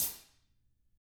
Index of /90_sSampleCDs/ILIO - Double Platinum Drums 1/CD2/Partition D/THIN A HATR